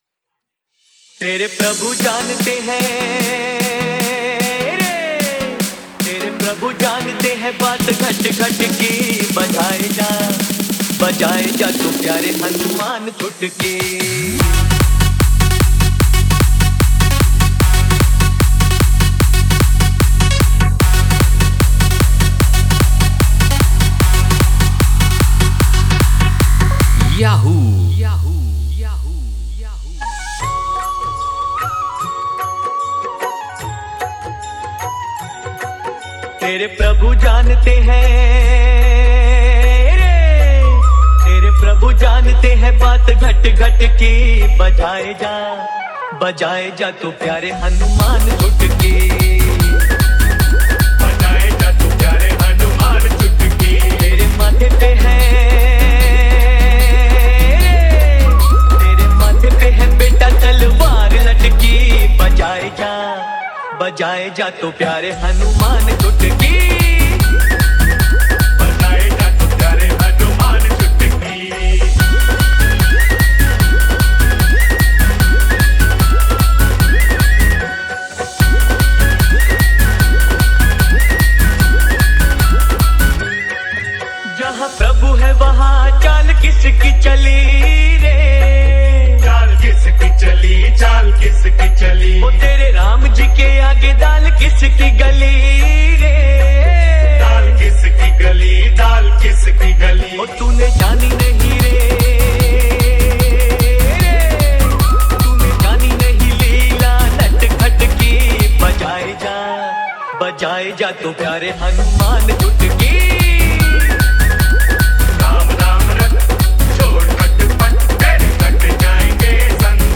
Category: Single DJ Tracks